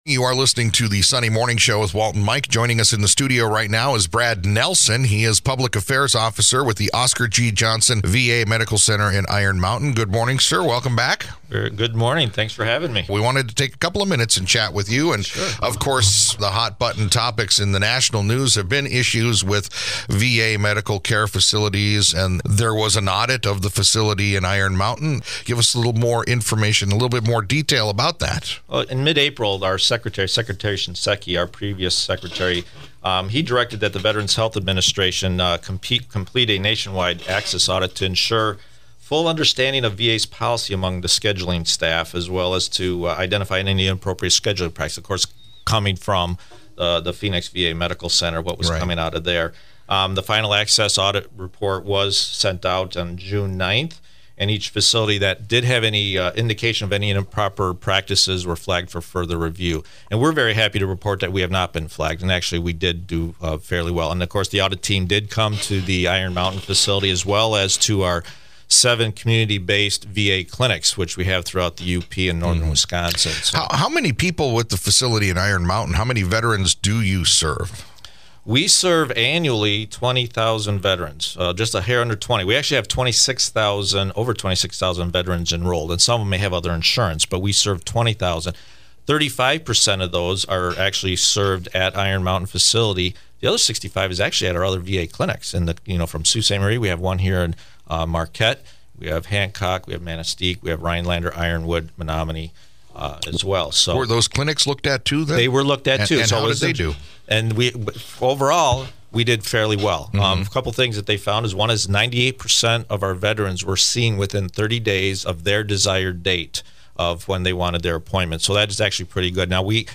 Best in Category – Investigative Story: VA Medical Center Interview after Iron Mountain Audit
WKQS-InvestigativeStory_VA_Hospital_Audit_2014.mp3